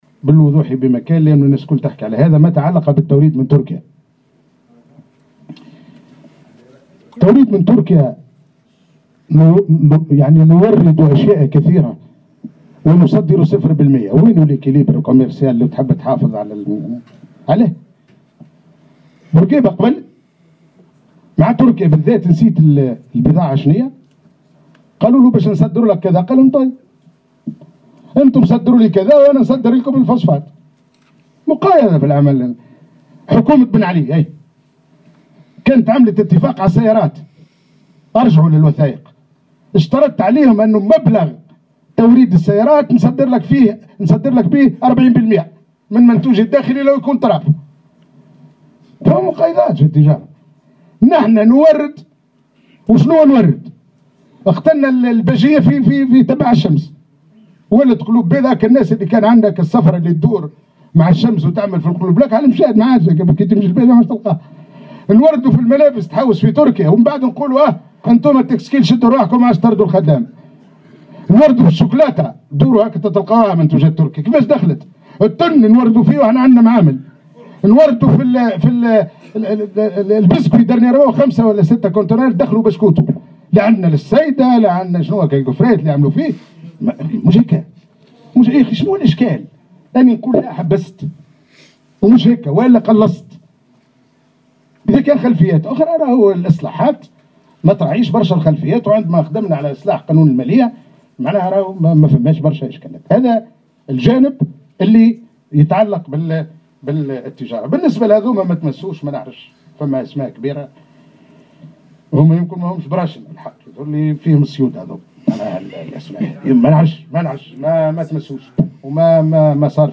وقال في ندوة صحفية